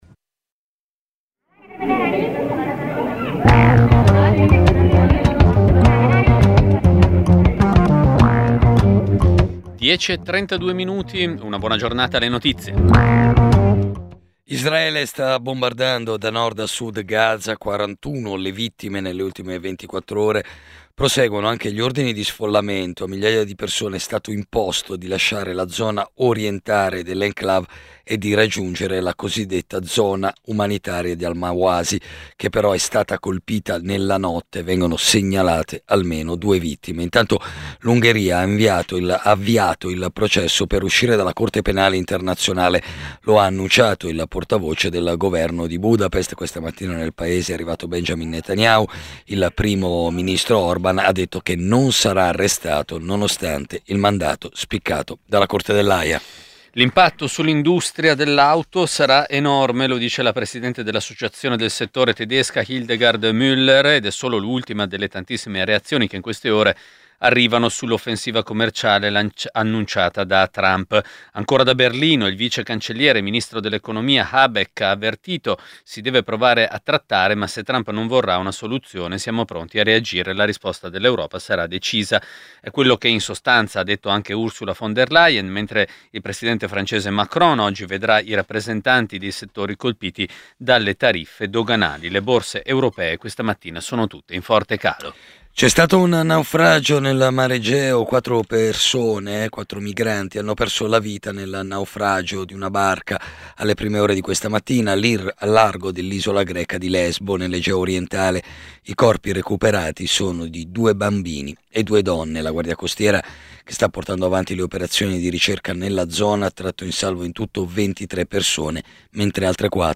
Giornale radio nazionale - del 03/04/2025 ore 10:32